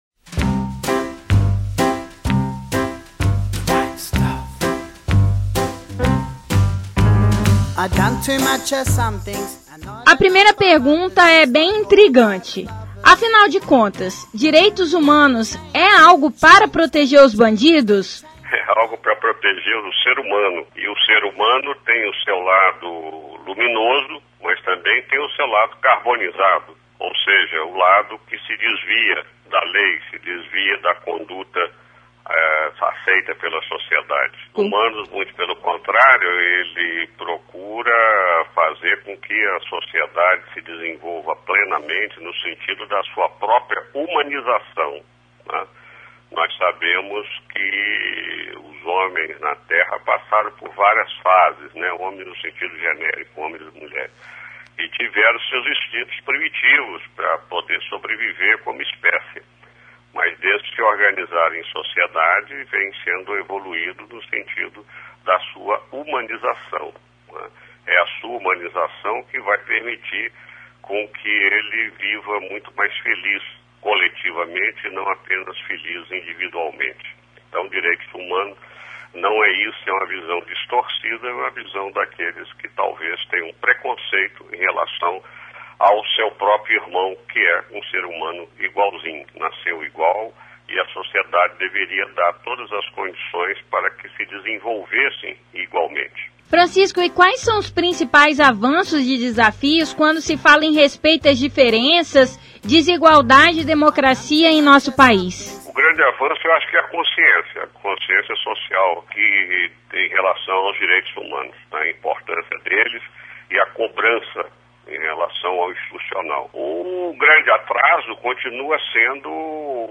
participou do Fórum e conversou com a equipe do Revista Universitária.